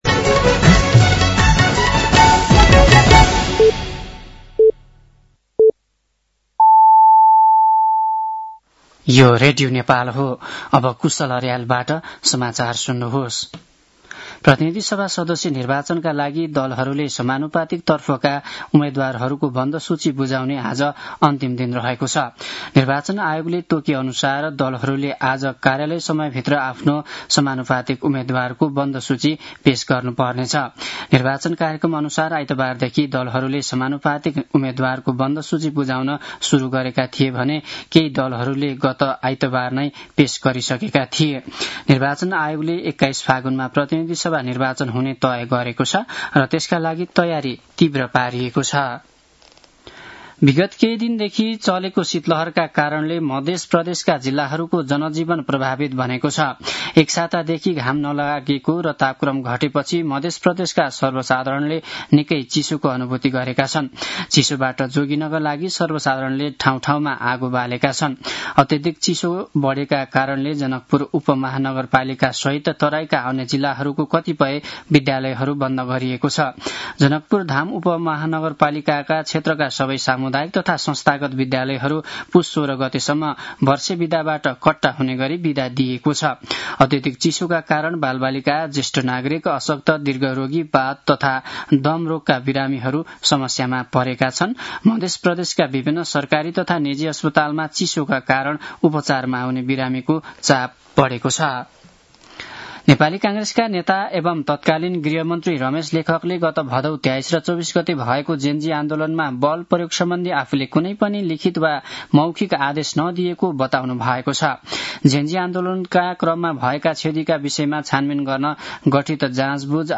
साँझ ५ बजेको नेपाली समाचार : १४ पुष , २०८२
5.-pm-nepali-news-1-6.mp3